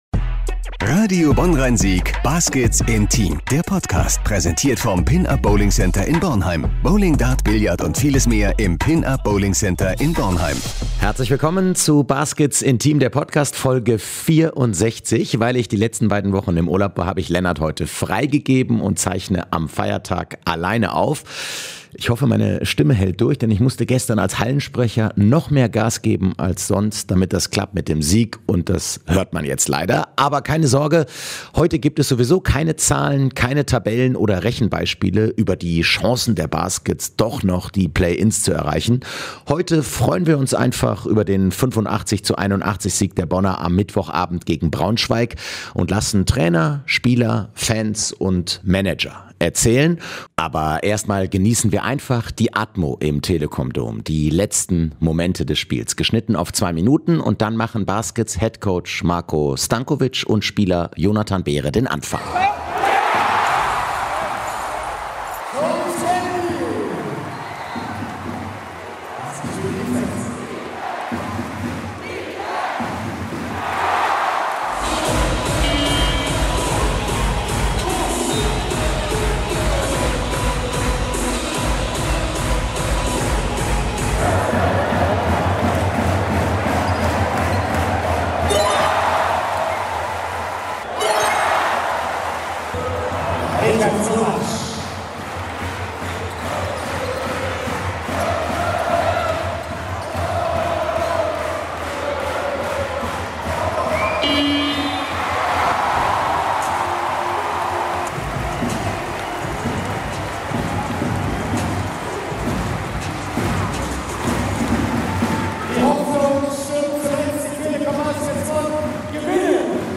Sie kommen in der aktuellen Folge natürlich zu Wort, aber auch Trainer und Spieler.